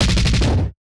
Drum Fill 1.wav